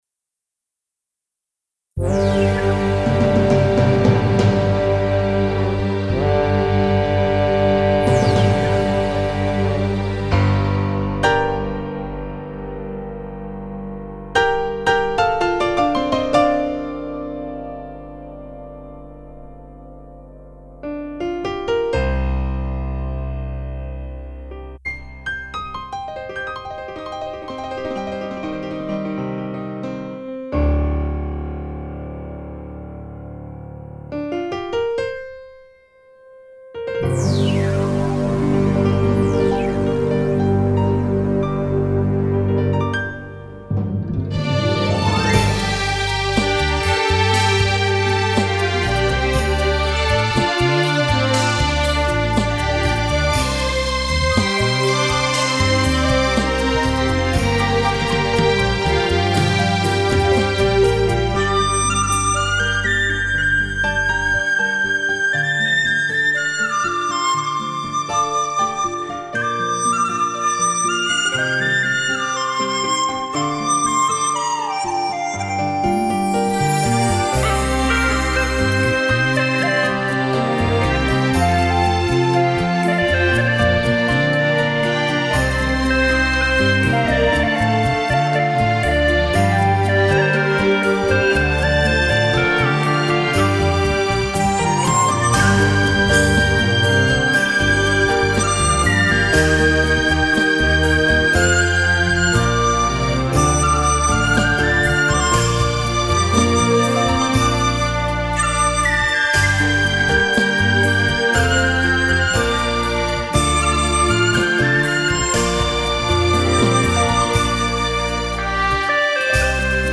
3周前 纯音乐 8